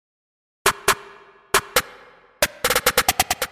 陷阱小鼓变奏曲2
Tag: 136 bpm Trap Loops Drum Loops 608.83 KB wav Key : Unknown